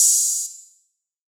DDW3 OPN HAT 3.wav